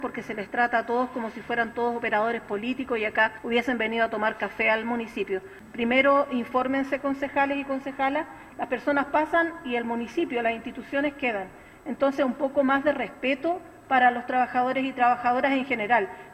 En la misma línea, la concejala comunista, Dafne Concha, lo emplazó a entregar las evaluaciones de los más de 150 funcionarios notificados, para ver si se justificaba o no el despido.